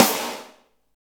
Index of /90_sSampleCDs/Roland L-CD701/KIT_Drum Kits 3/KIT_Pop Kit 1
SNR PICCOL02.wav